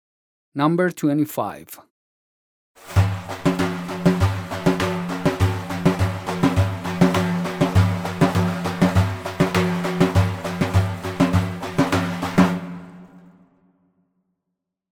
Daf and Taraneh Lesson Sound Files